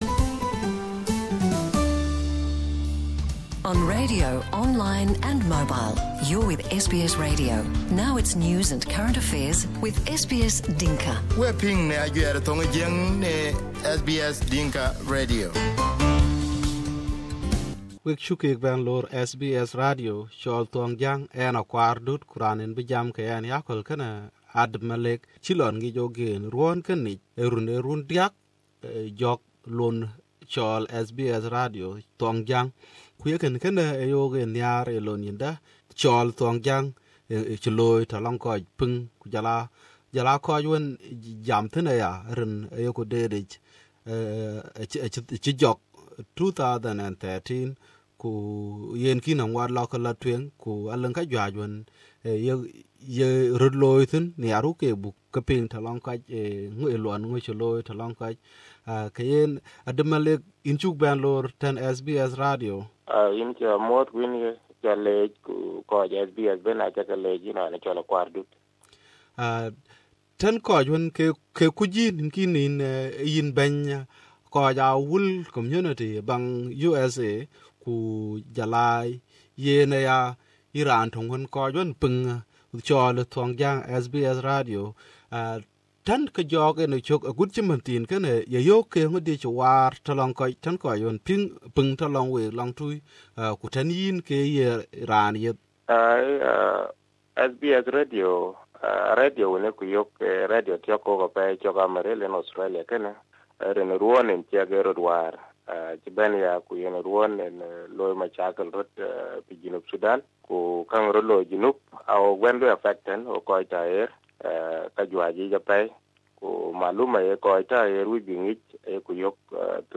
photo Interview Source